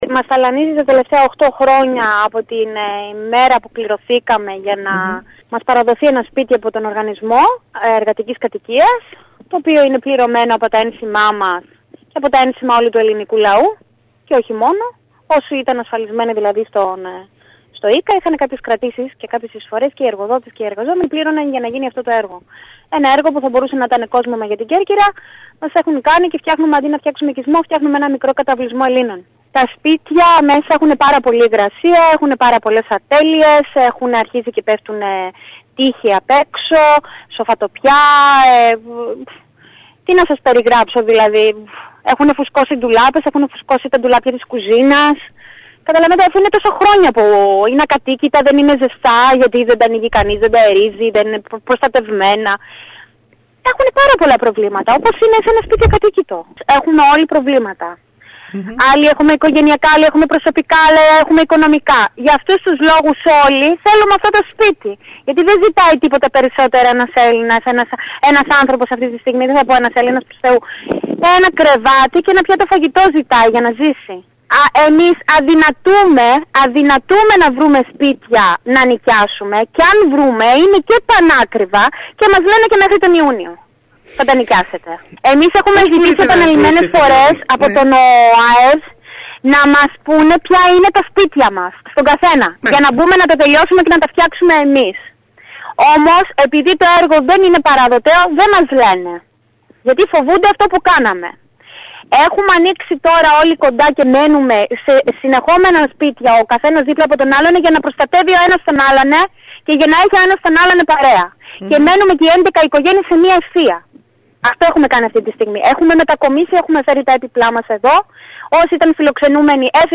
Στα σπίτια τους στον Άγιο Ιωάννη “μπήκαν” το Σαββατοκύριακο πάνω από 11 οικογένειες δικαιούχων των εργατικών κατοικιών, οι οποίοι όπως επισημαίνουν  περιμένουν  ήδη 8 χρόνια για να τους αποδοθούν χωρίς κανένα αποτέλεσμα. Μάλιστα όπως είπαν μιλώντας στην ΕΡΤ  δεν ανέχονται άλλο τον εμπαιγμό από τις αρμόδιες υπηρεσίες του ΟΑΕΔ και υπογράμμισαν ότι θα μείνουν στα σπίτια τους με κάθε κόστος.